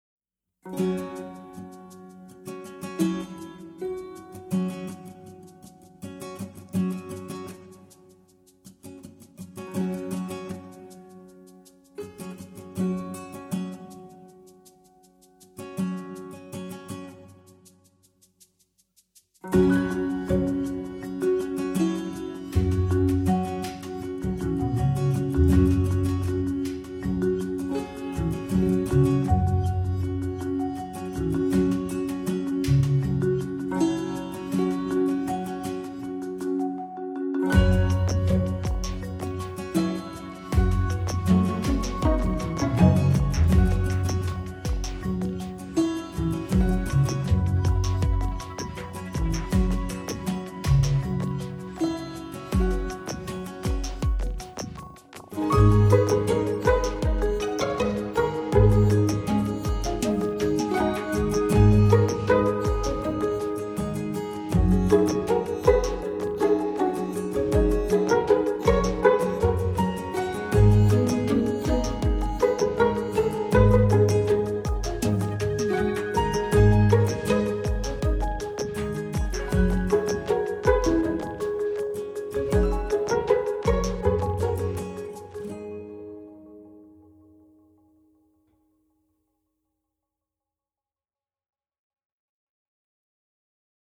less guitars